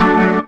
B3 DMAJ 1.wav